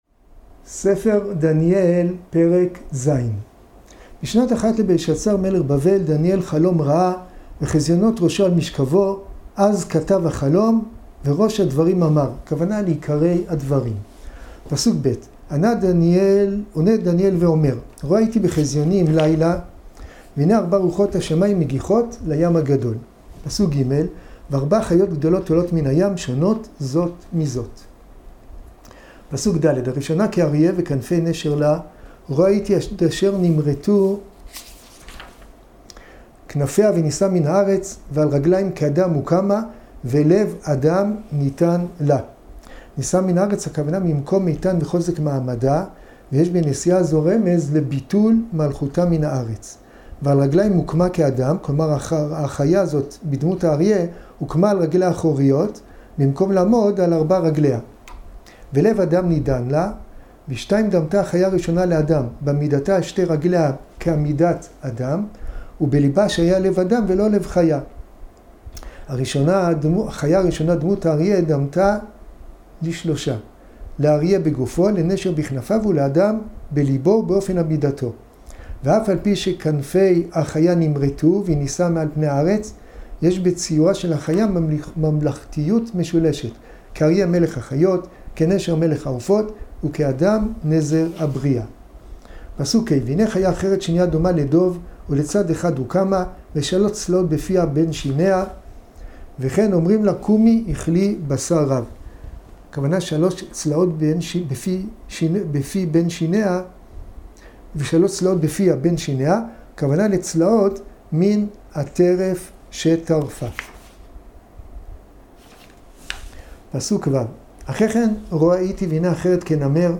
שיעורים בתנ"ך - ספר דניאל ספר דניאל פרק א גלות יהויקים.